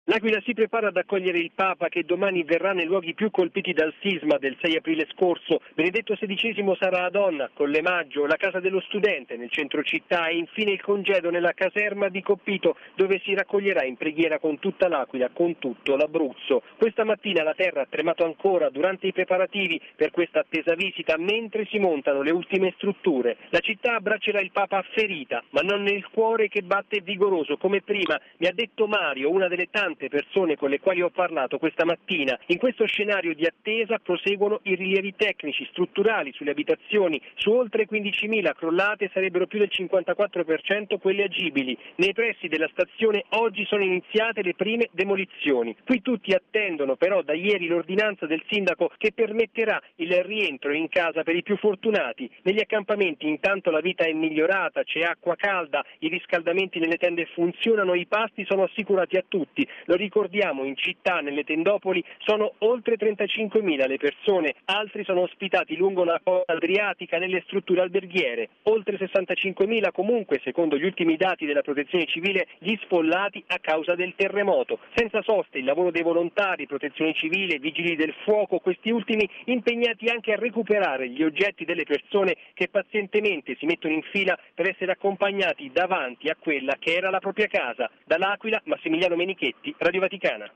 Ma diamo la linea ad uno dei nostri inviati in Abruzzo